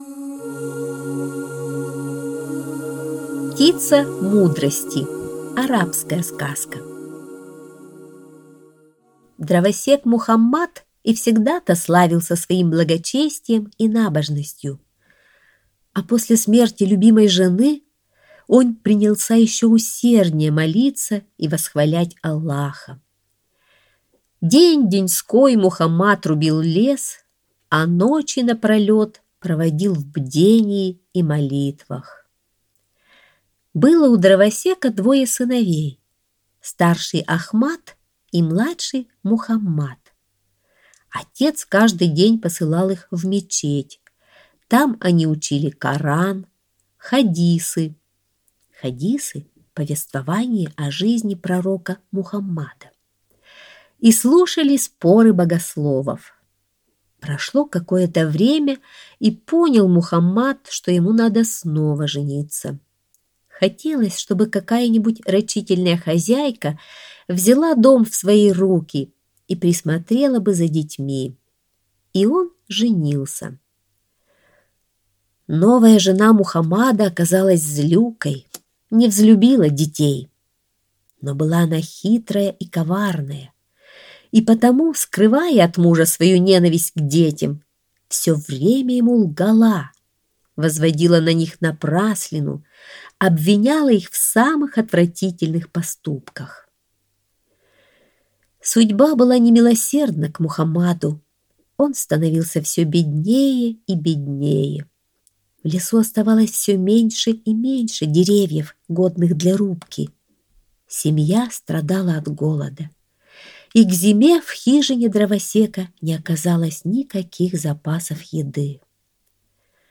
Птица мудрости - арабская аудиосказка - слушать онлайн